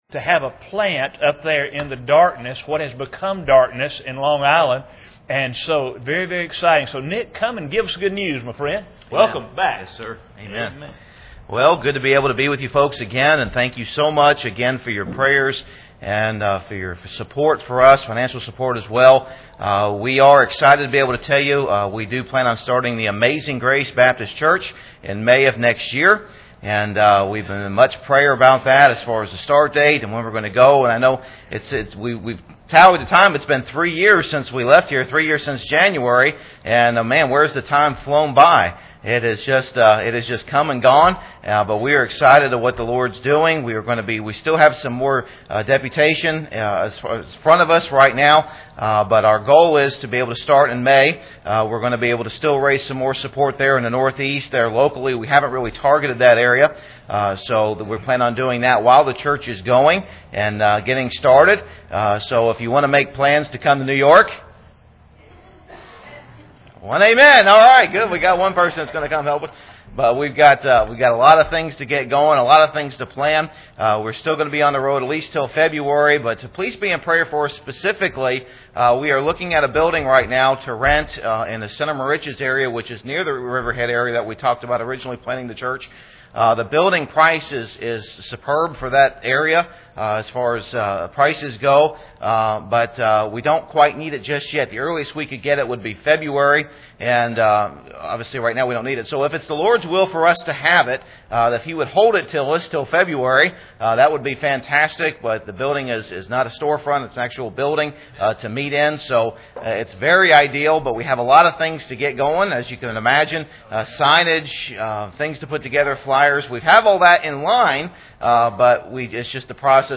“Wilds Testimonies” – Four different groups have recently visited the Wilds Christian Camp in North Carolina. This evening we hear testimonies from several teens and adults who went.